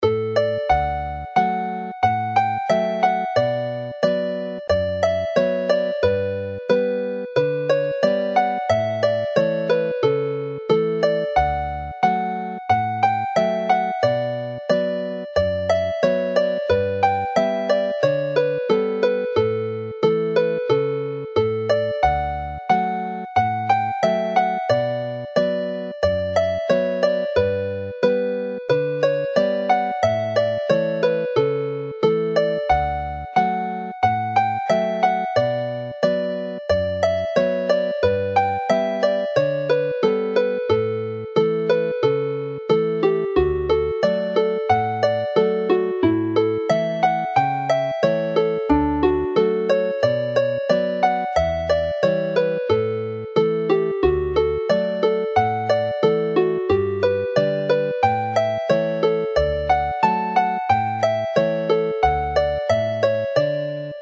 Dowlais Hornpipe (as a reel)
Play the melody slowly